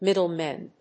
• / ˈmɪdʌˌlmɛn(米国英語)
• / ˈmɪdʌˌlmen(英国英語)